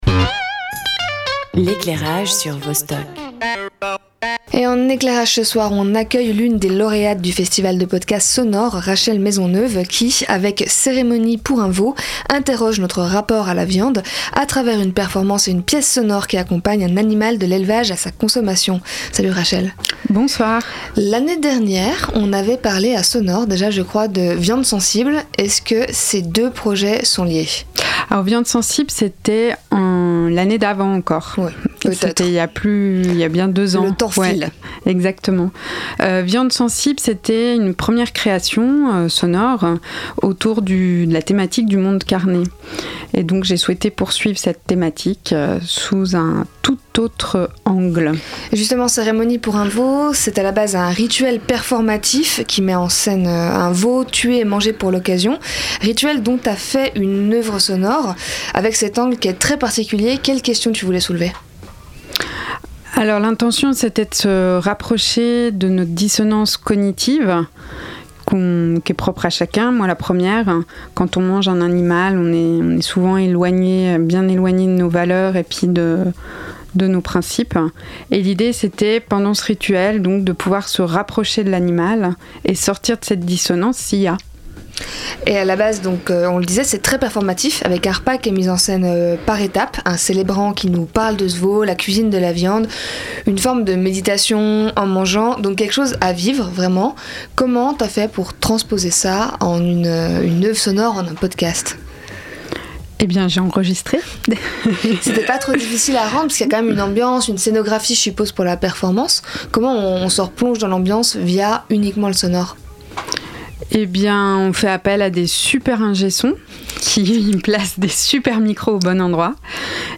Invitée
Animation